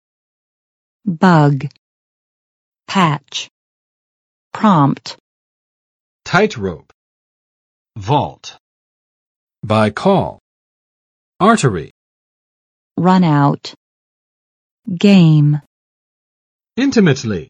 Vocabulary Test - December 15, 2021
[bʌg] n.【计算机】（程序中的）错误
bug.mp3